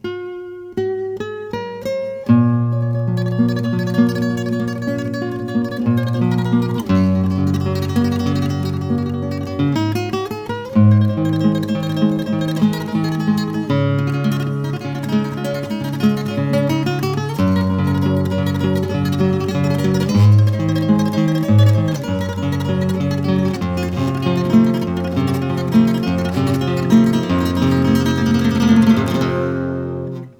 Taranta. Toque de guitarra. Flamenco.
taranta
flamenco
guitarra